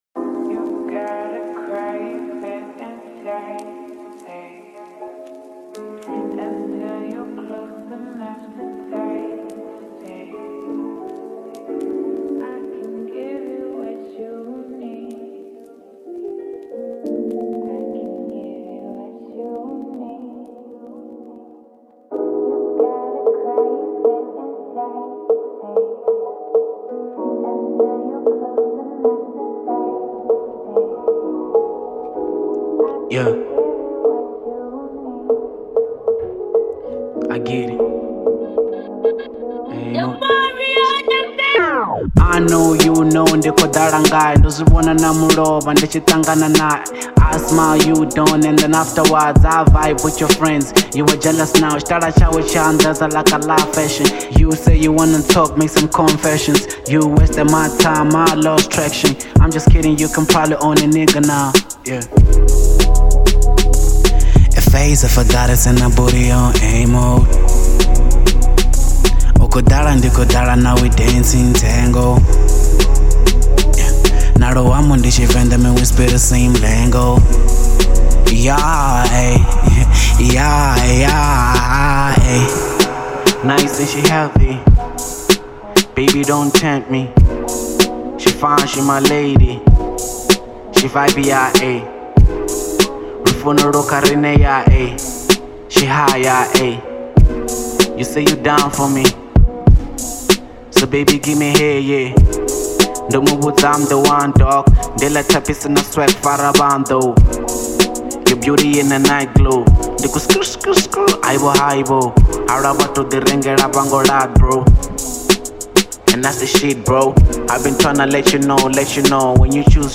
04:02 Genre : Venrap Size